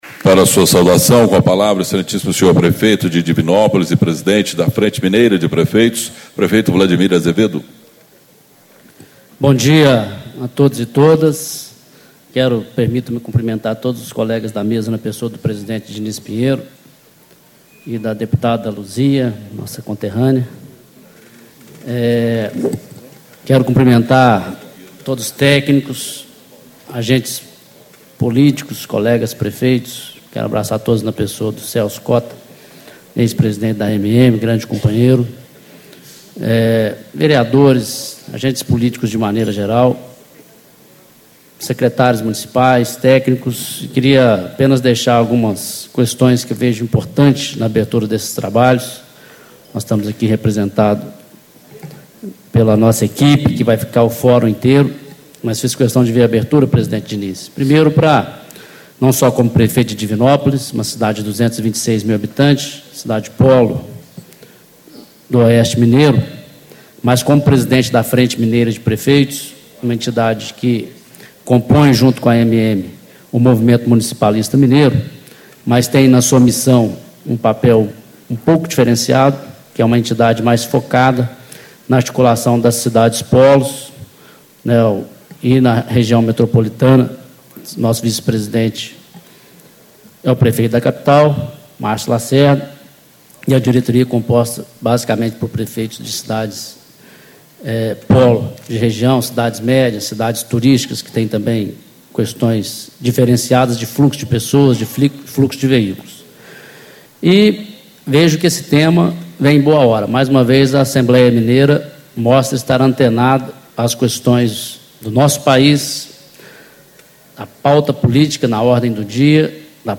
Abertura - Vladimir Azevedo - Prefeito de Divinópolis e Presidente da Frente Mineira de Prefeitos
Encontro Estadual do Fórum Técnico Mobilidade Urbana - Construindo Cidades Inteligentes